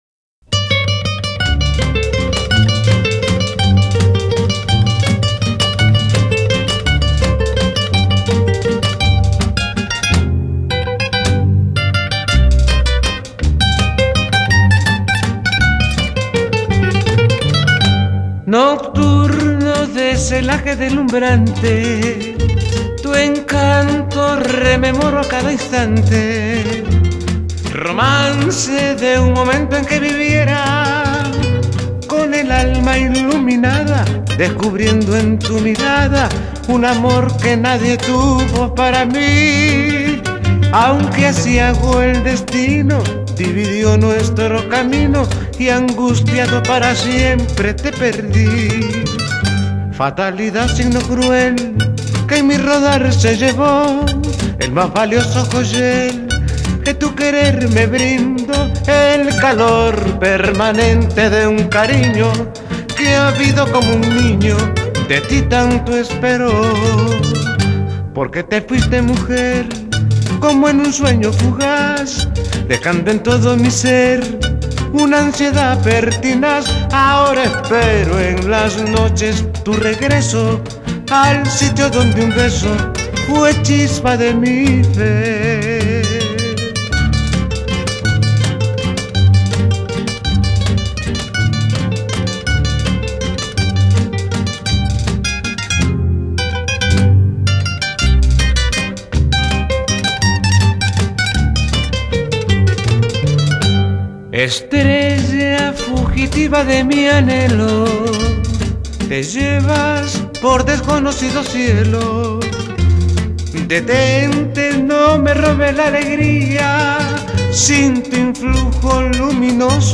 Vals